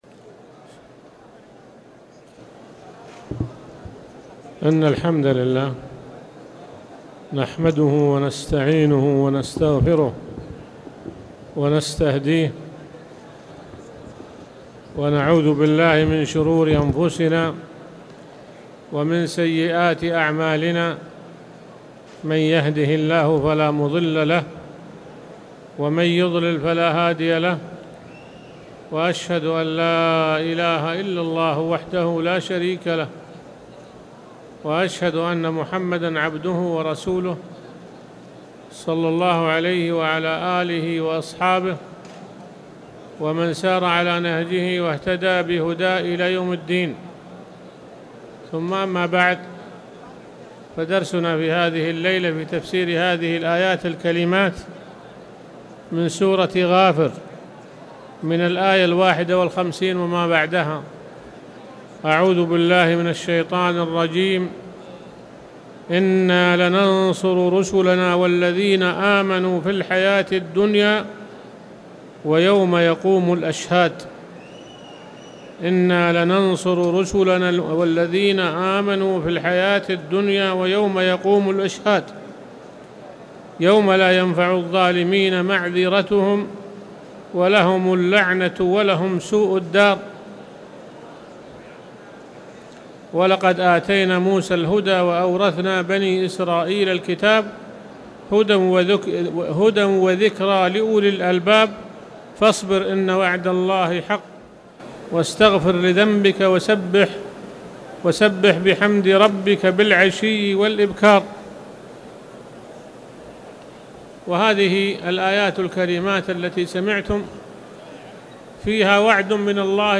تاريخ النشر ٢١ جمادى الآخرة ١٤٣٨ هـ المكان: المسجد الحرام الشيخ